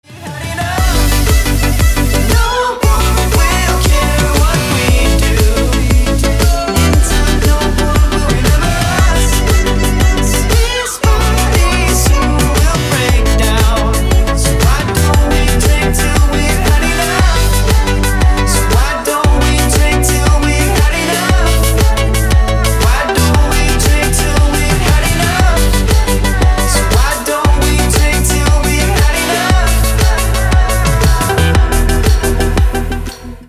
мужской вокал
красивые
dance
спокойные
vocal